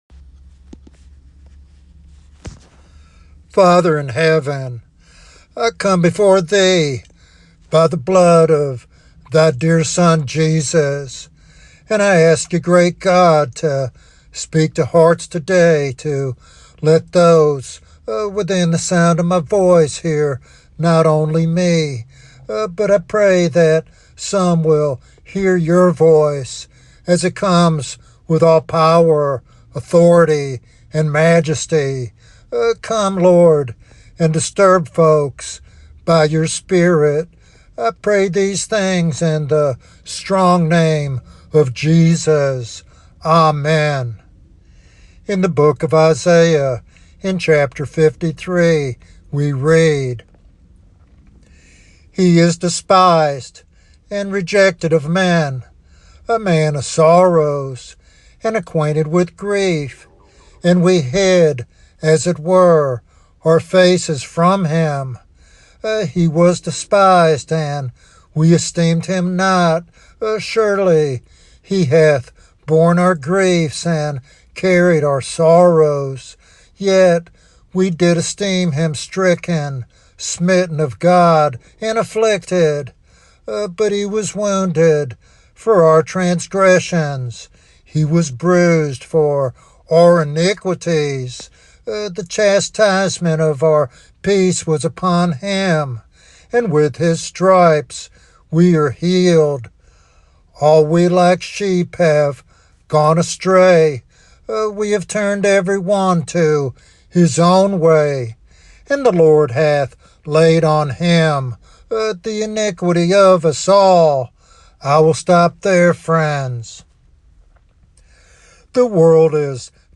This evangelistic sermon calls listeners to a radical commitment to the crucified Savior who alone offers salvation.